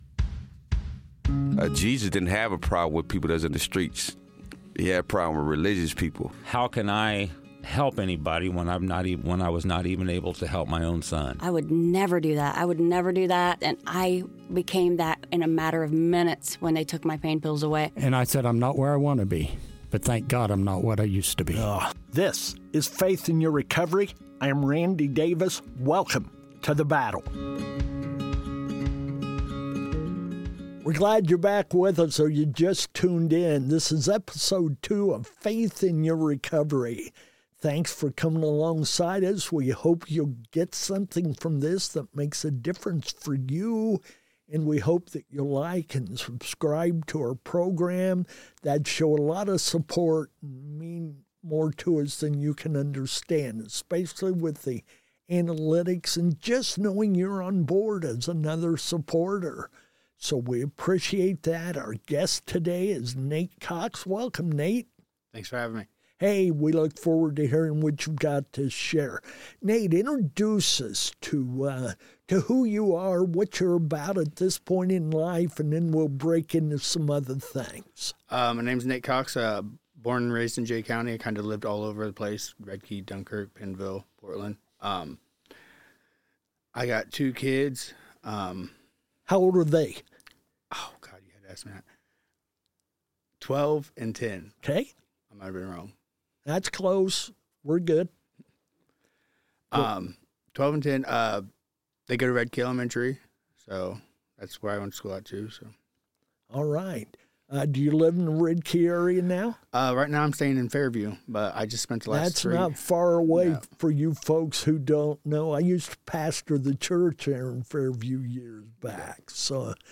Join us we interview inmates about their addiction and recovery experiences. This episode was produced from inside the Jay County Security Center.